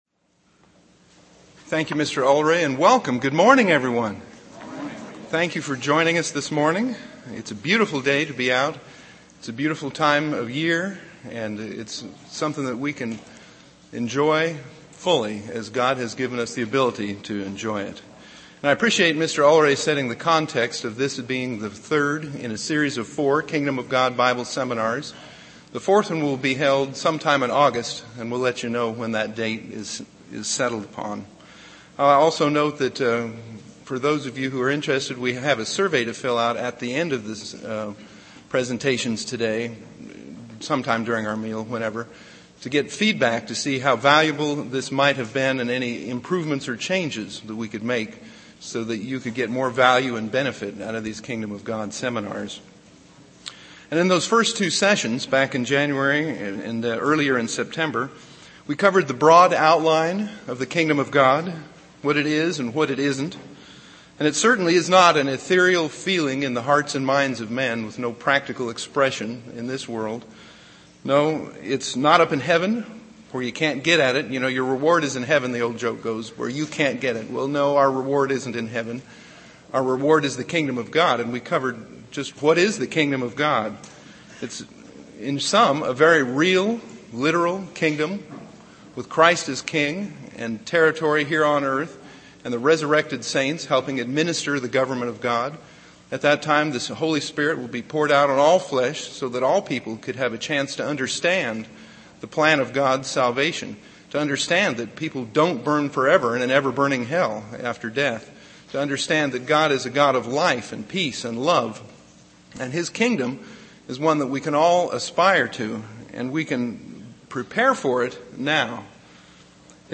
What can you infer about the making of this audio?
This message was given for a Kingdom of God seminar.